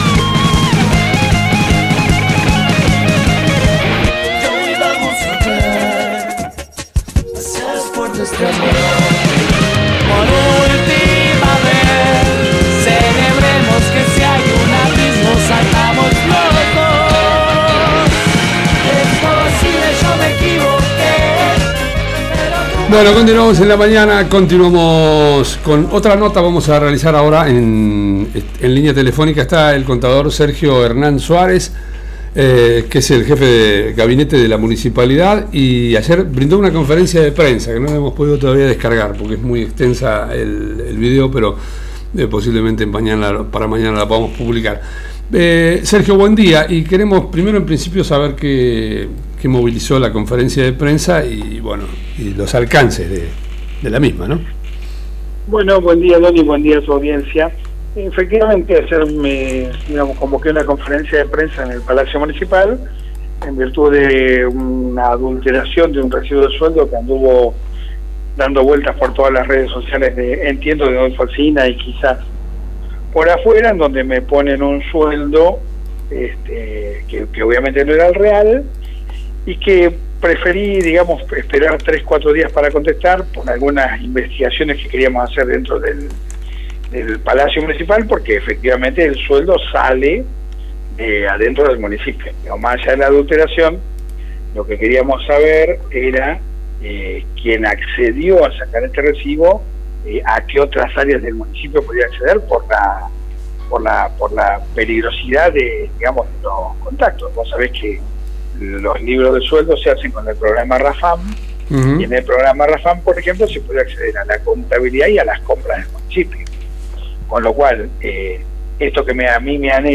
El Jefe de Gabinete del Municipio realizó un descargo y denuncia por la publicación de un recibo de sueldo trucho a su nombre. En la entrevista exclusiva dad a nuestro medio explicó los alcances de este acto que consideró “una truchada”.